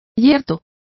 Complete with pronunciation of the translation of rigid.